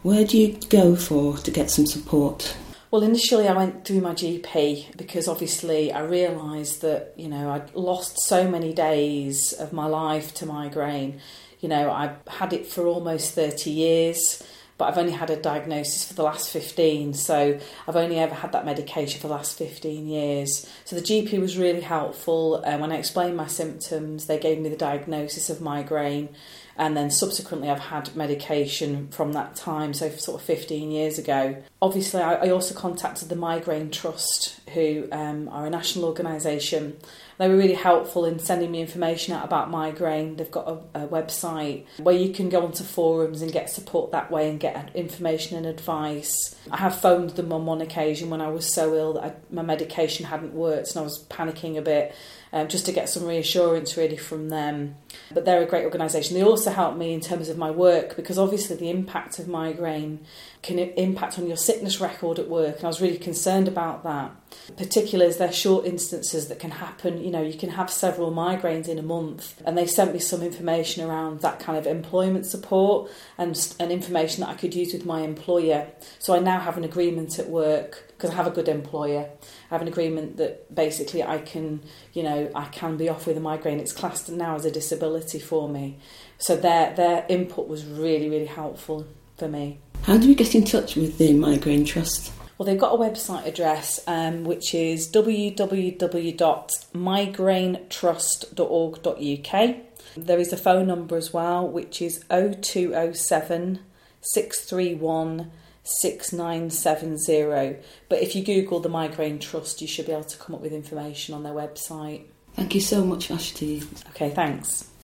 Migraine Interview no. 2